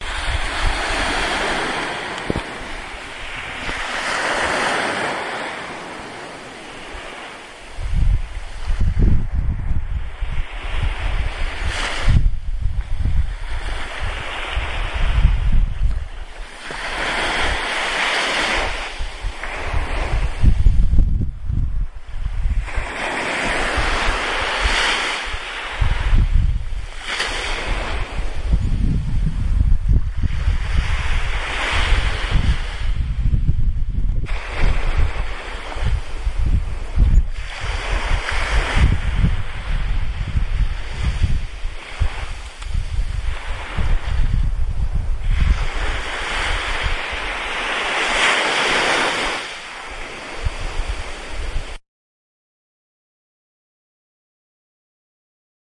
西班牙的地中海浪潮之声
描述：沿着西班牙太阳海岸的地中海滚动的小波浪的声音。可以听到一些风吹过麦克风头。